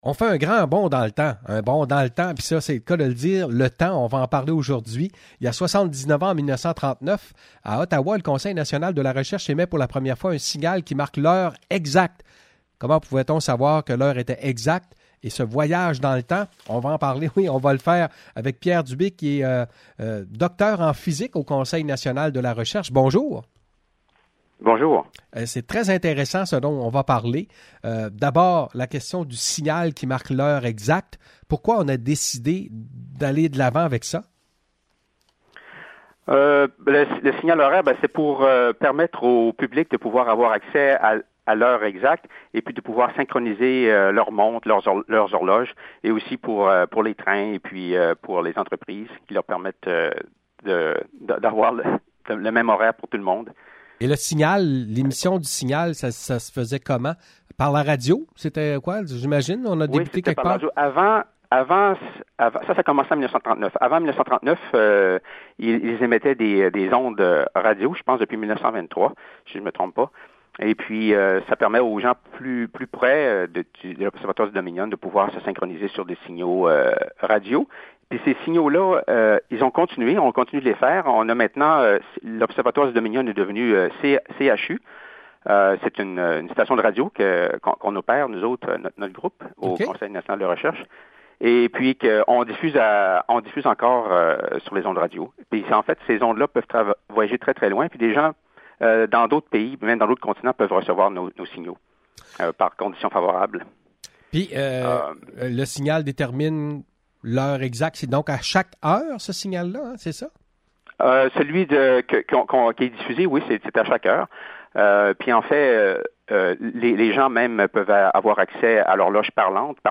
Entrevue avec le Dr en physique